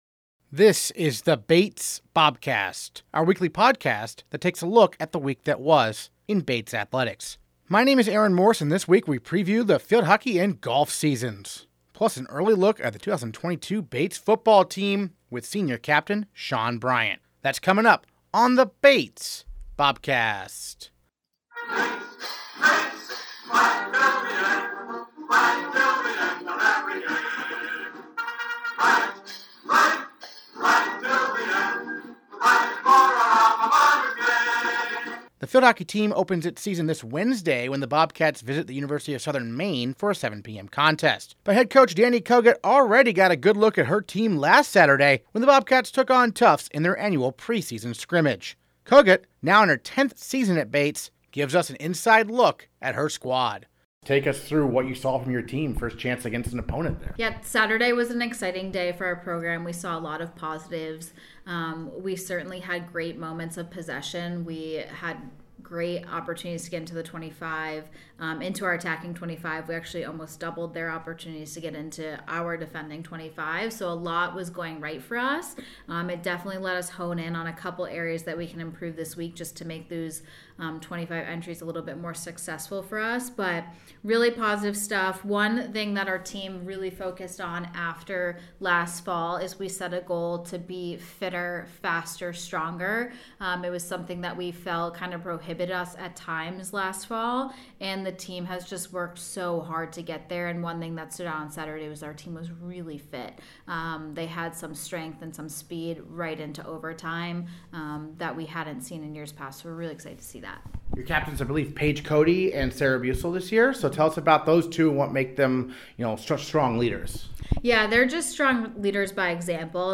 Interviews this episode: